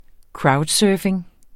Udtale [ ˈkɹɑwdˌsœːfeŋ ]